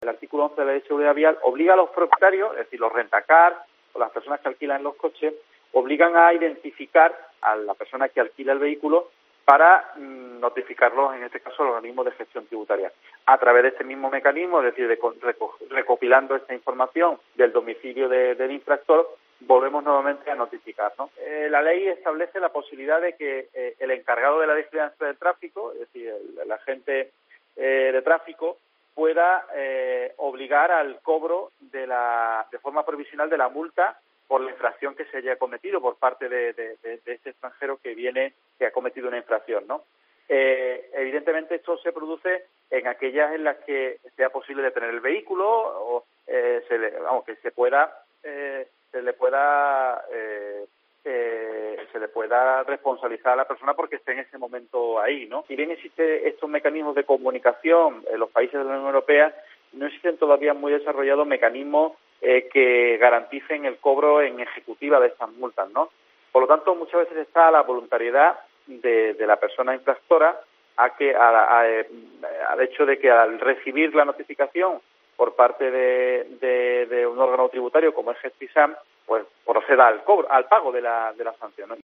Carlos Conde en COPE Málaga sobre seguridad vial